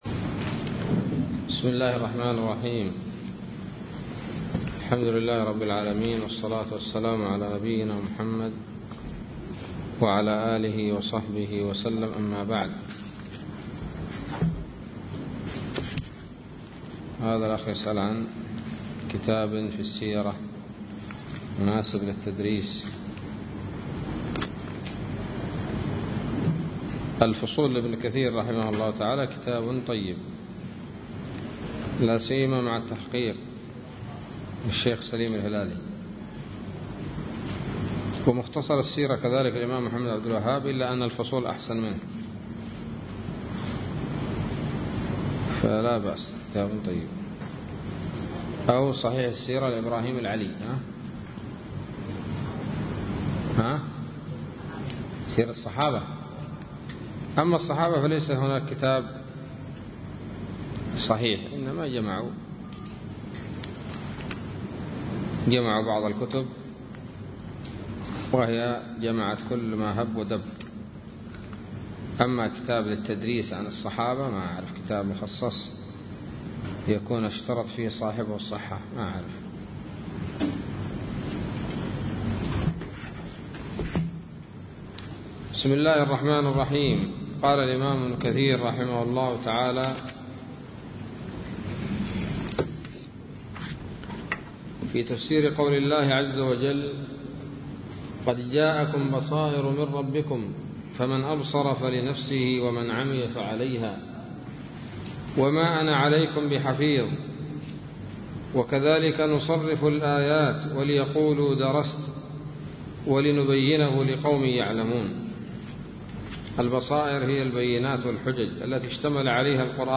الدرس الثامن والثلاثون من سورة الأنعام من تفسير ابن كثير رحمه الله تعالى